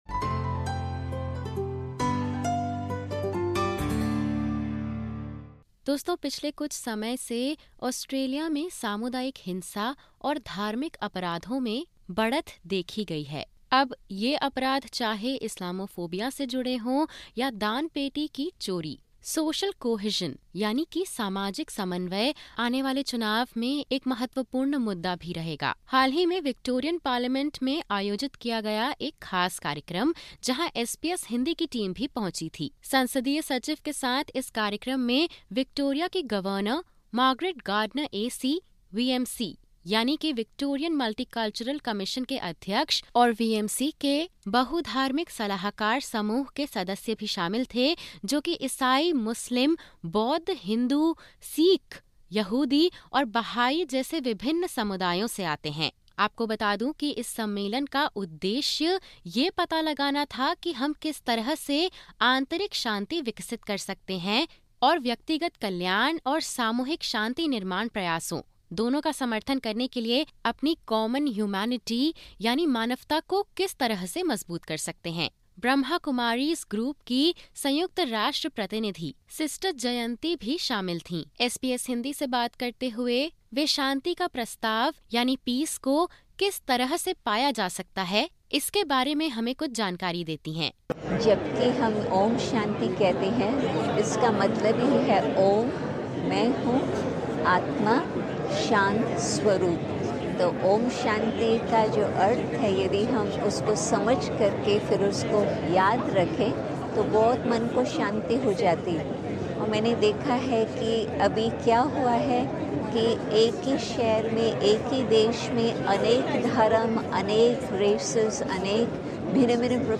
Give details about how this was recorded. To address challenges to peace and social cohesion, the Victoria Multicultural Commission held a peace-building forum at the Victorian Parliament. The event united leaders from various religious backgrounds to discuss inner peace, compassion, and our shared humanity